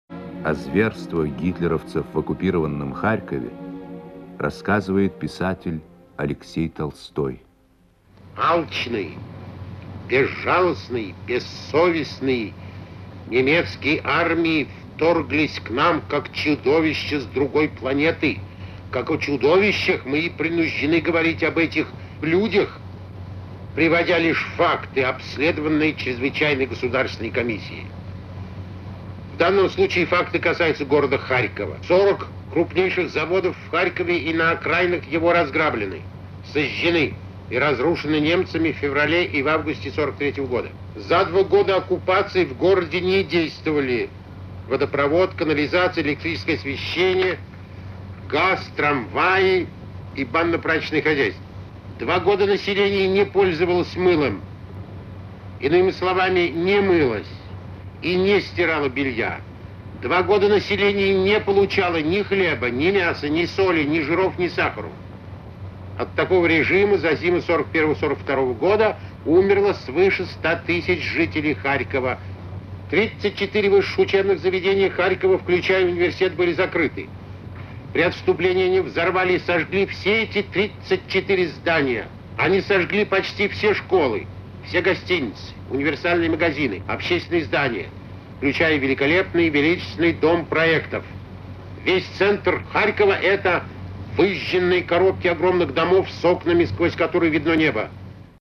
Писатель Алексей Толстой рассказывает о зверствах гитлеровцев в оккупированном Харькове (Архивная запись).